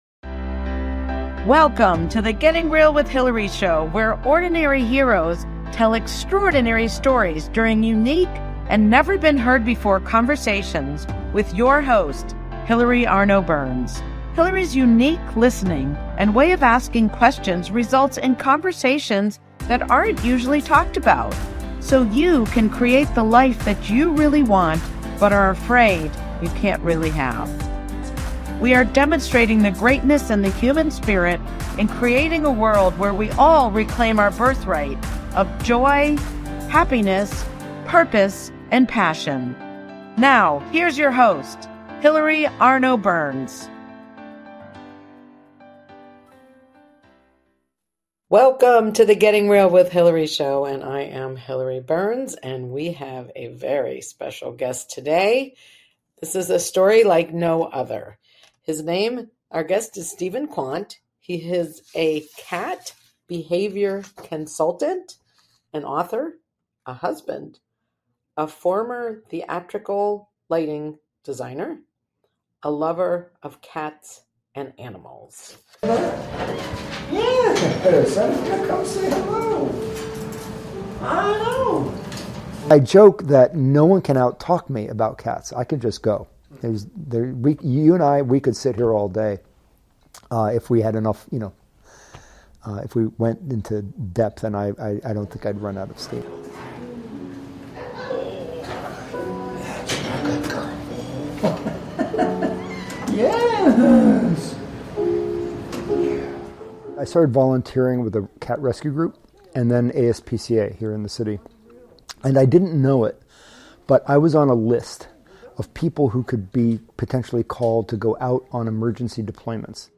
Talk Show Episode
This interview blew my mind.